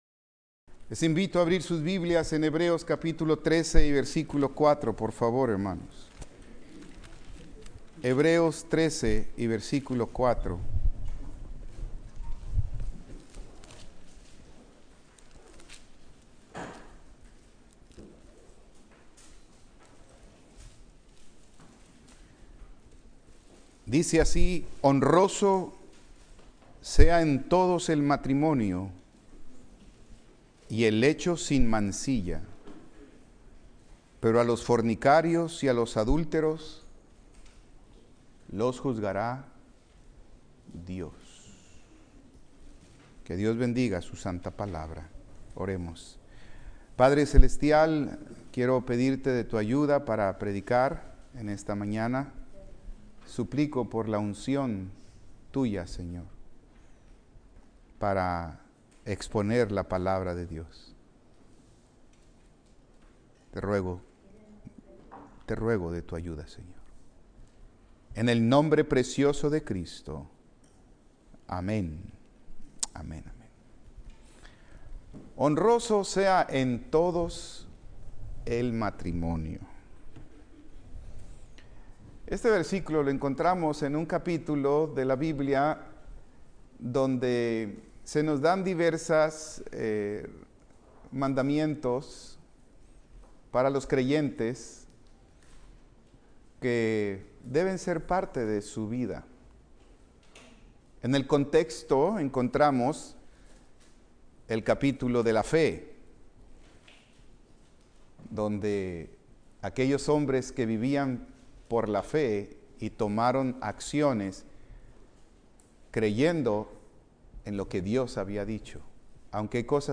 Servicio matutino